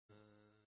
مدح و شهادت حضرت زهرا (س) زمینه -( با شور ِ مشتاقی همه میخونیم آیه ی نور )